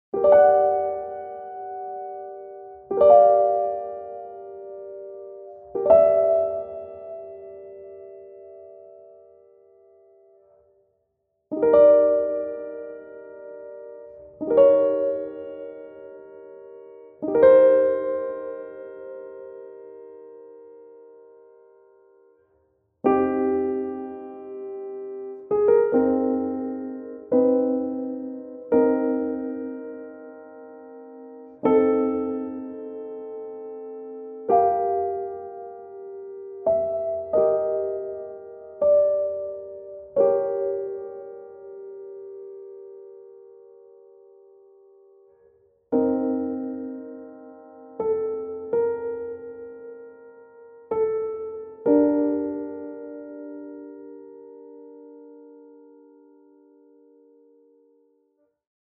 不安な気持ち、ミステリアスな雰囲気を表すピアノのジングルです。